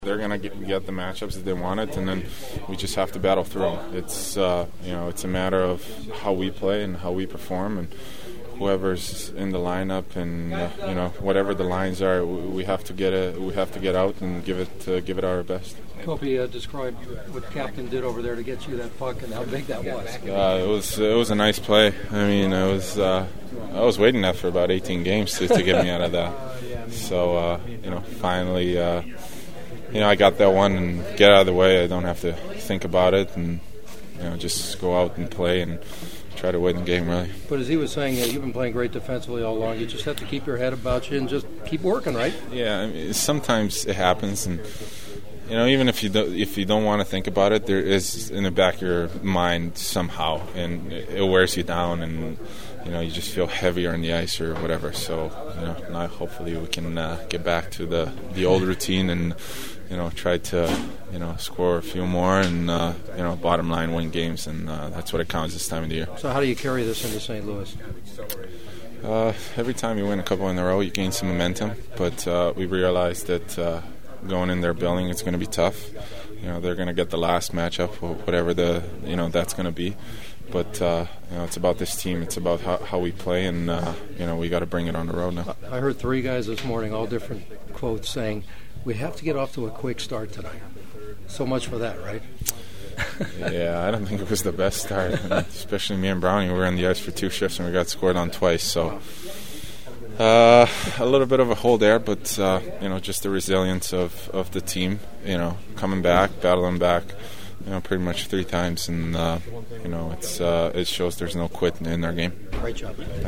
The following are my postgame chats from the locker room and they were all cautiously stoked about their chances during the rest of this series…
Kings center Anze Kopitar finally had a personal reason to smile again: